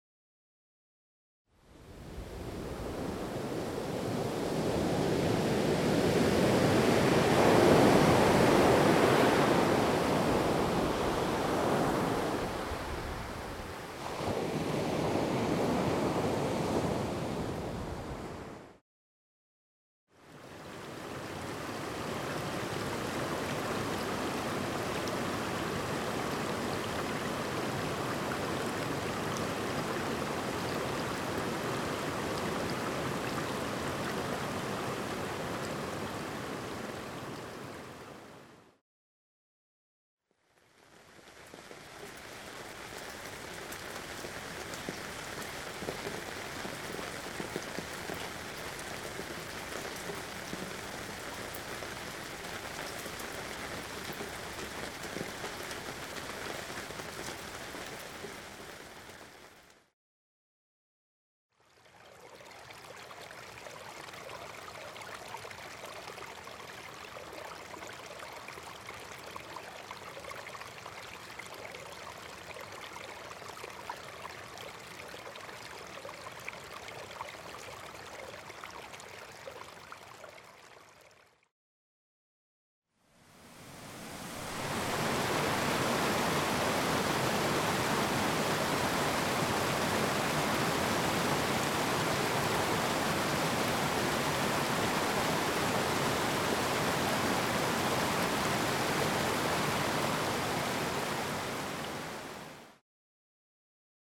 H2O the sounds of water
Our primal mind is in tune with the archetypal sounds of nature, water being one of the most powerfully balancing of them all.
Each song gently fades in and out for smooth continuous play when being looped.
Rain:        Washington State’s Peaceful Rain Shower  60 Min.
Stream:    Oregon’s Trickling Winter Run Off  60 Min.
web_sample_of_h20.mp3